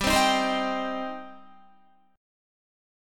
Gsus4#5 chord